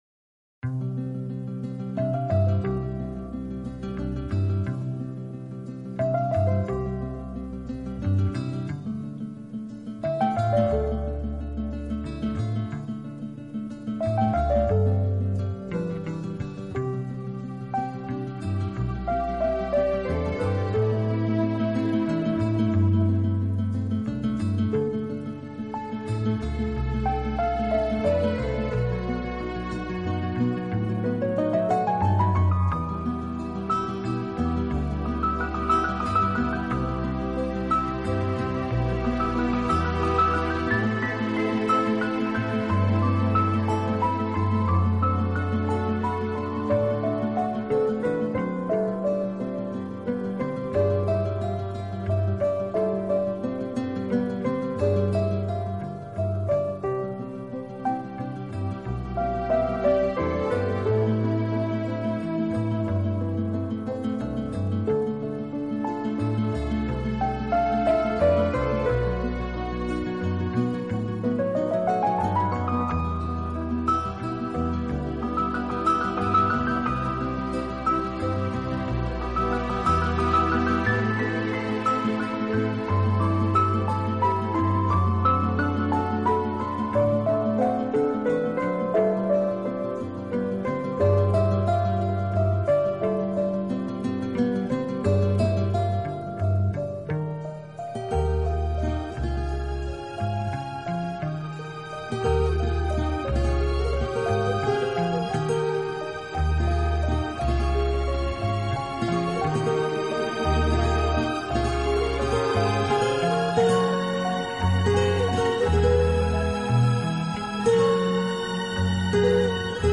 【欧美浪漫】
浪漫、最优美的纯音乐，超时空经典，超想像完美。
当您在聆听浪漫音乐的时候，优美，舒缓的音乐流水一样缓缓抚过心田，你会觉